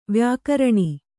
♪ vyākaraṇi